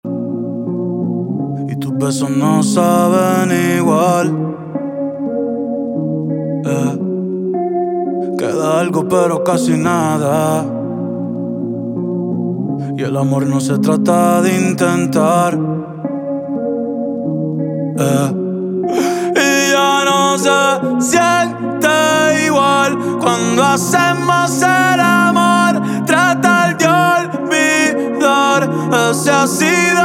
• Urbano latino